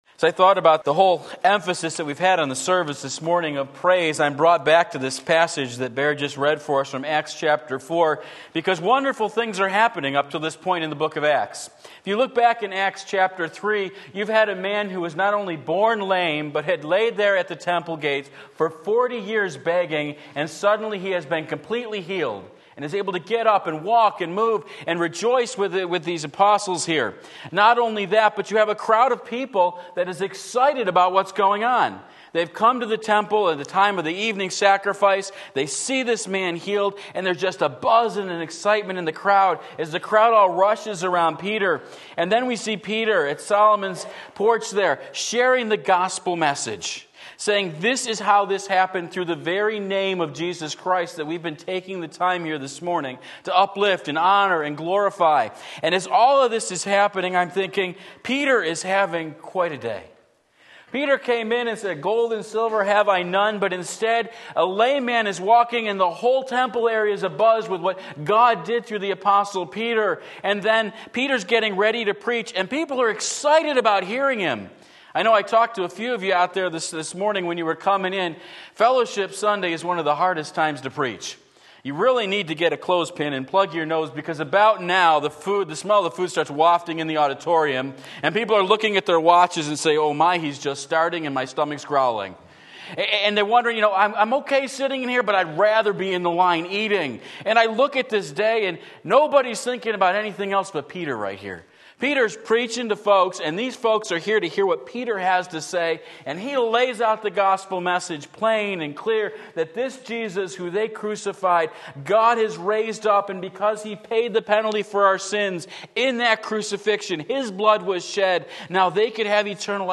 Sermon Link
Acts 4:1-22 Sunday Morning Service